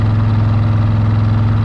KART_Engine_loop_1.ogg